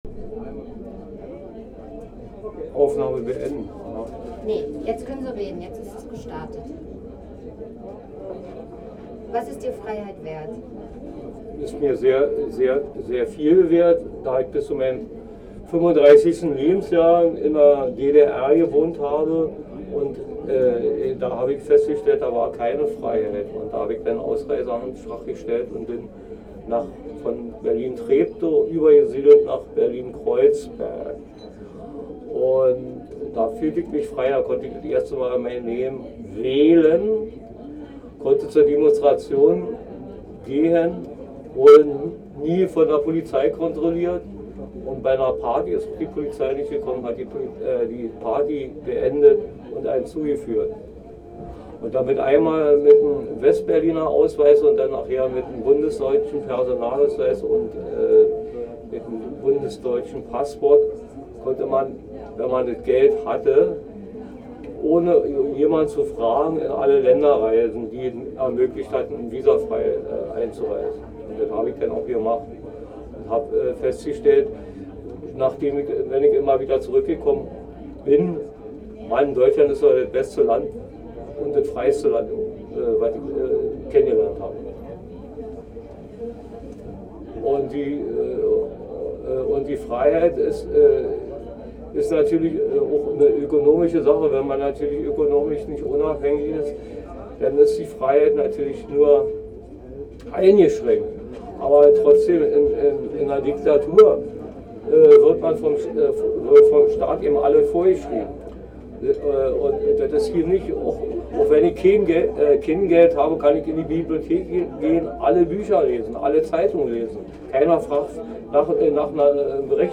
Ein Fest für die Demokratie @ Bundeskanzleramt, Berlin